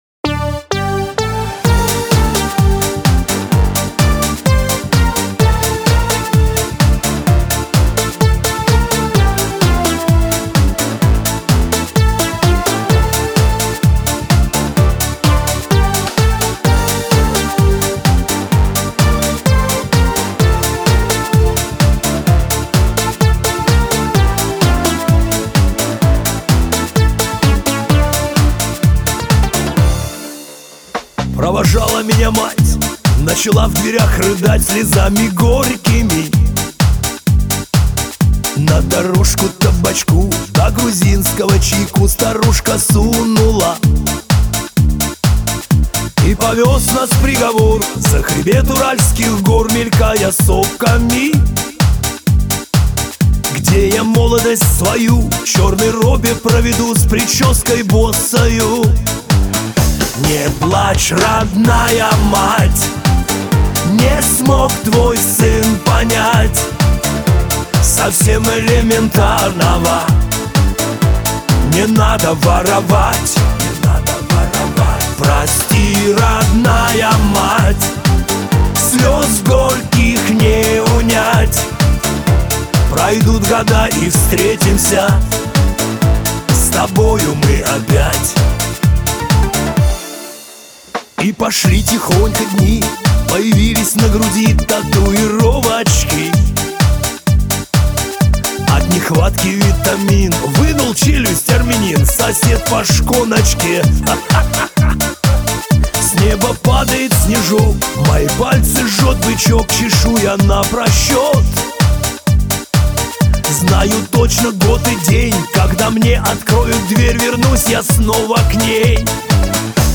душевная песенка